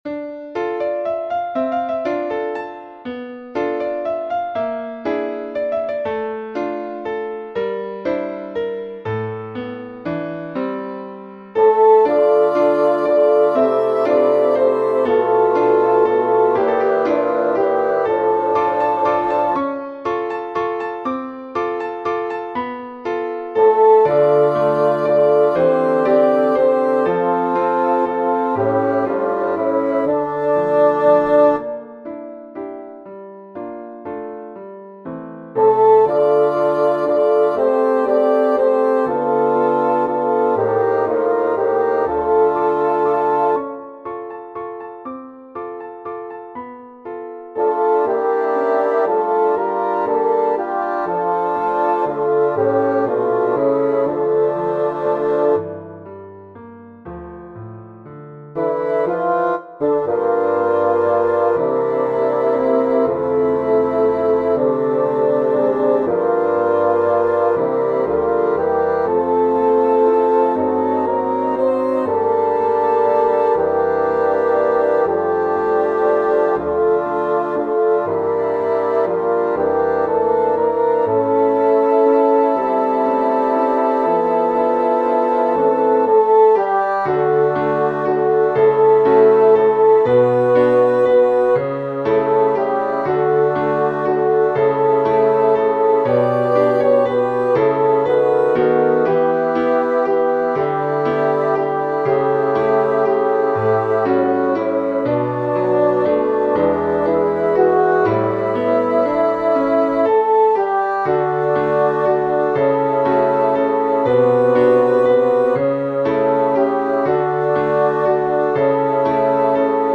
S   A T  B = Sopraan, Alt, Tenor, BAS